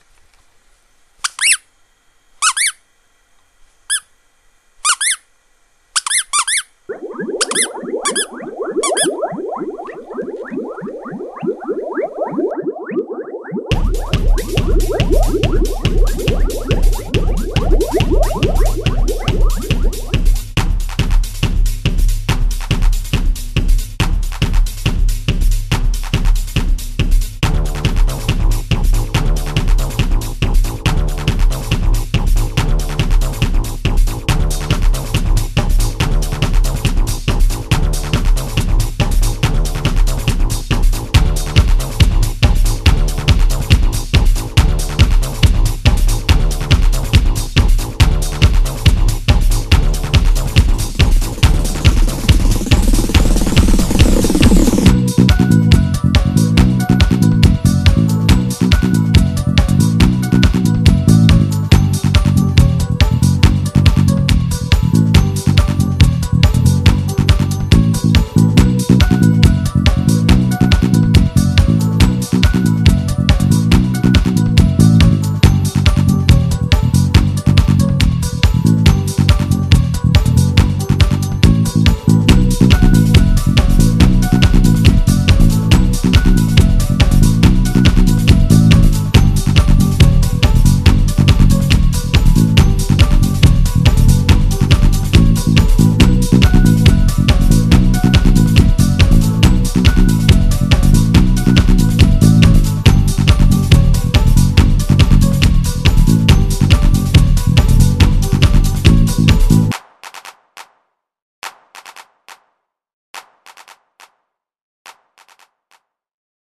ダンス音楽系のノリですから、そういうのが好きではない人は避けた方が良いと思います。
最初に入る変な音は，同機の内蔵スピーカで録音したデータを利用。